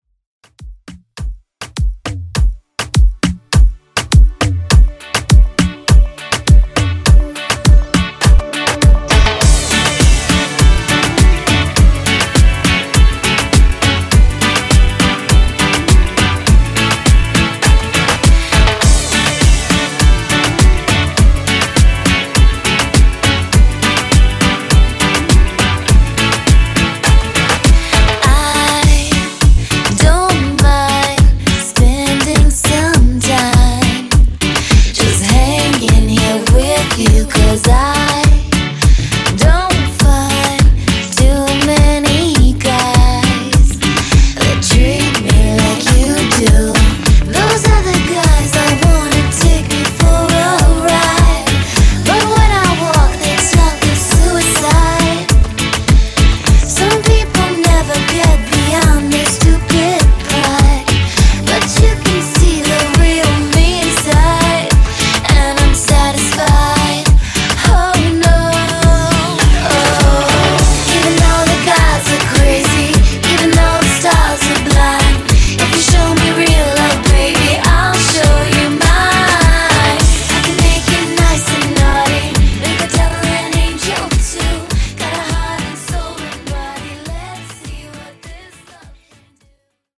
Afro Re-Drum)Date Added